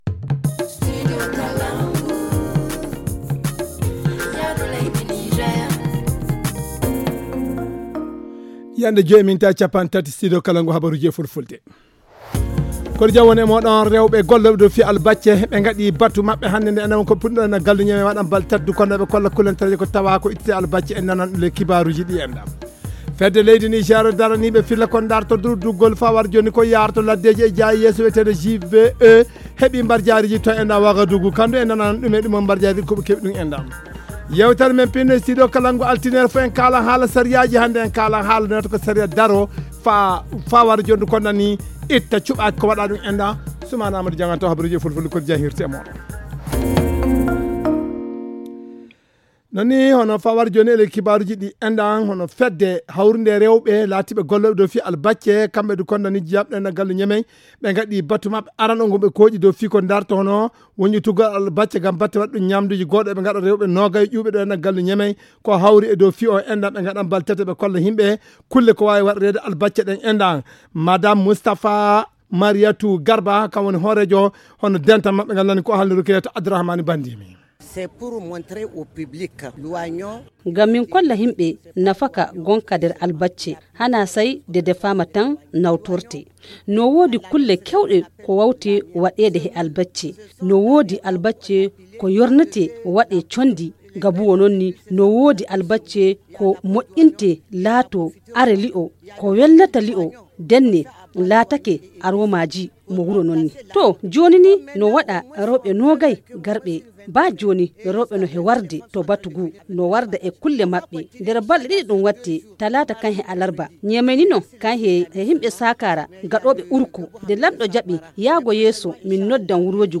Le journal du 25 octobre 2021 - Studio Kalangou - Au rythme du Niger